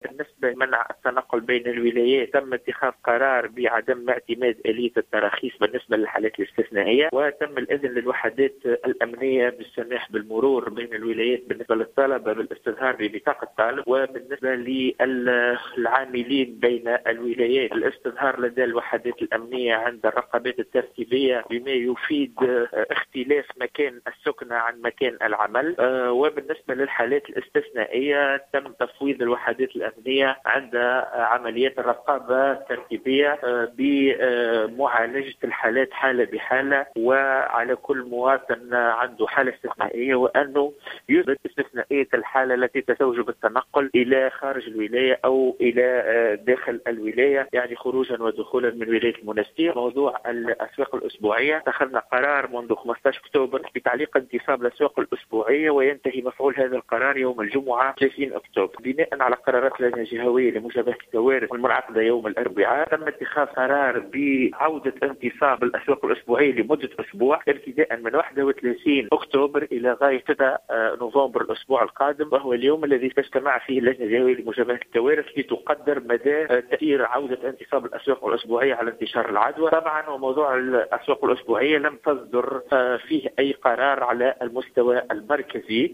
وأوضح الوالي في تصريح للجوهرة أف أم ، أنه لم يتم إتخاذ قرار بغلق الأسواق على المستوى المركزي.